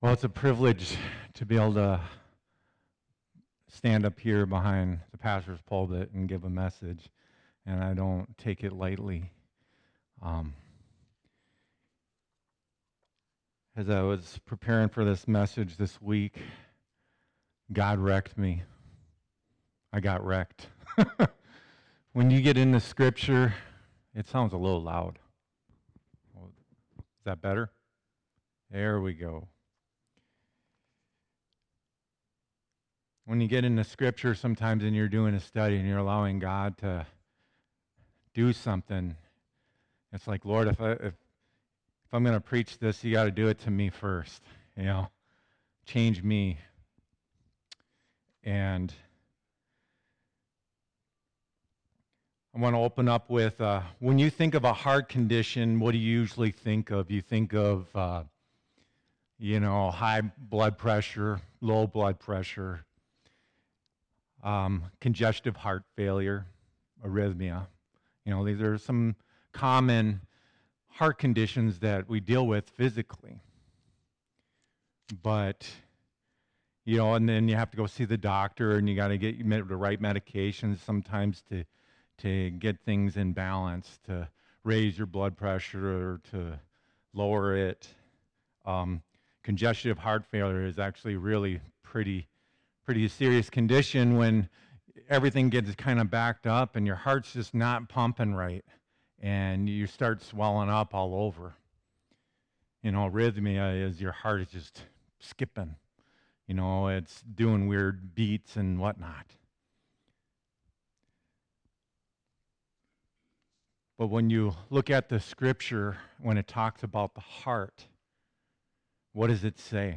2024 Heart Condition Preacher